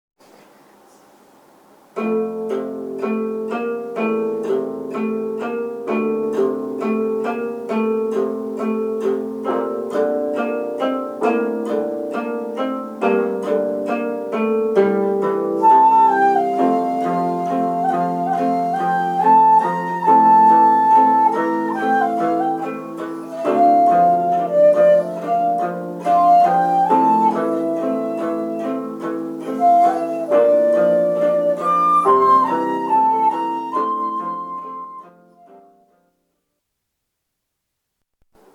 すなわち、お琴の弦を弾いてチューナーのランプが緑に点灯するまでしぶとく合わすのです。
しかも無意味とは知りながら17本すべてこのやり方で調弦してみました。
なにかキーボード的な響きで、全て濁った音になっています。